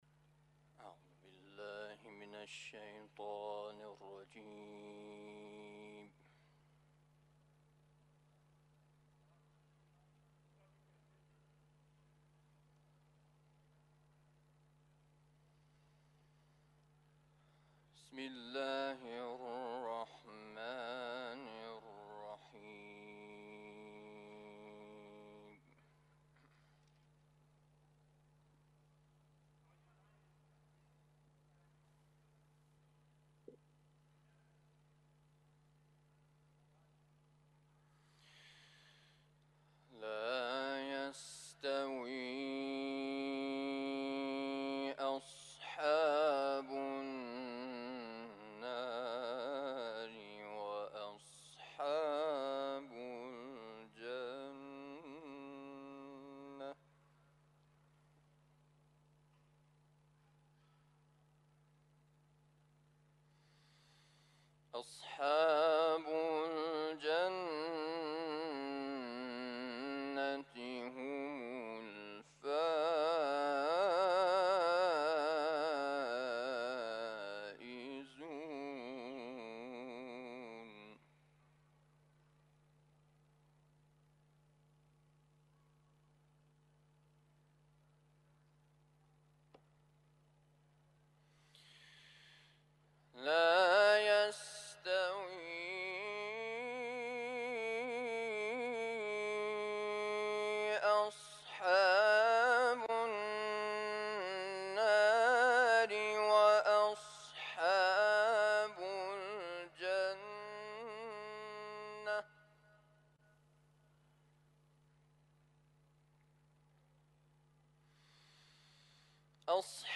تلاوت
قاری